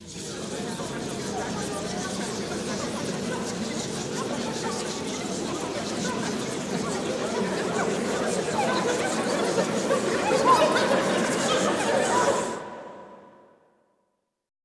Index of /90_sSampleCDs/Best Service - Extended Classical Choir/Partition I/VOICE ATMOS